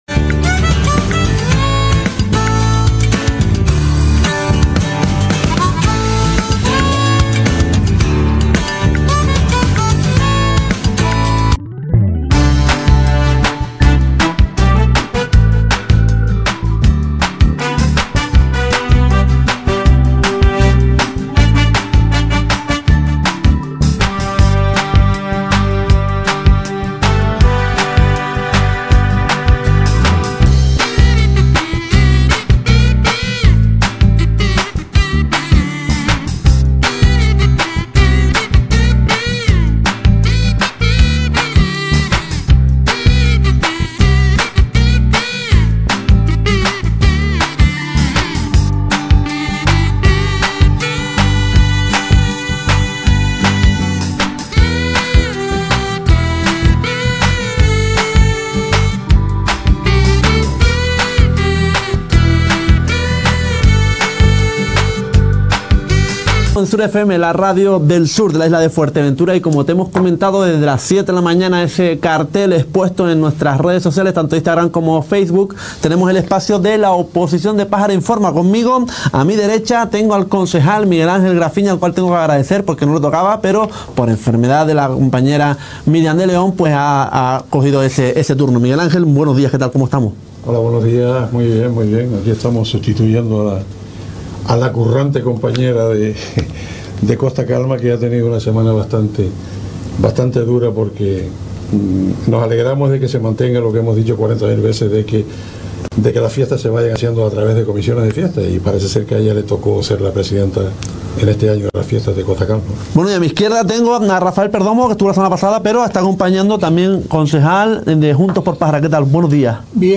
Acudía a nuestros estudios los concejales, Rafal Perdomo de Juntos X Pájara y Miguel Ángel Graffigna, concejal de Nueva Canarias.